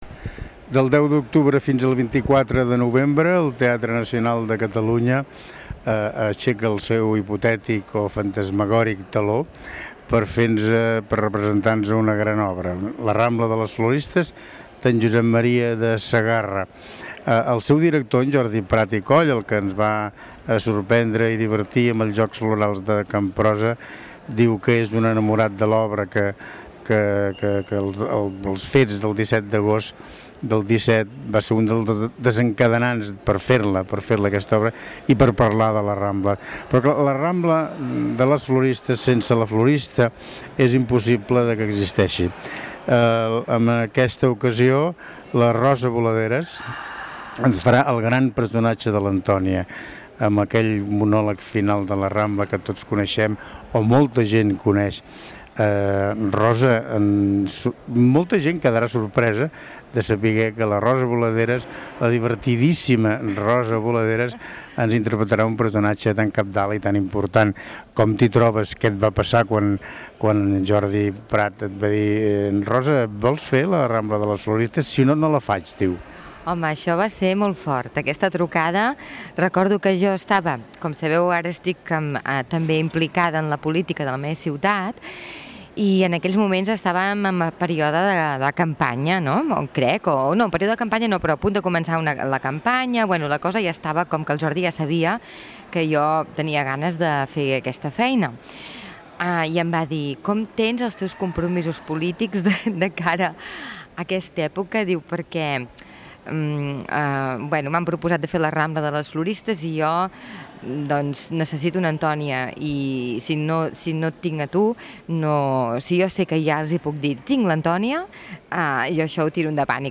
durant l'entrevista